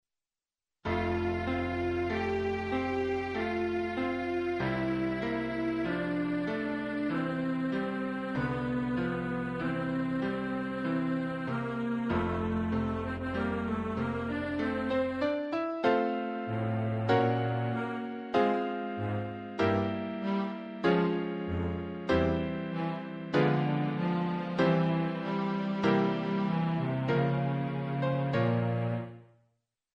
序奏のアダージョに話を戻すと、６小節目からチェロの旋律的なモチーフがあらわれそれをピアノが模倣する。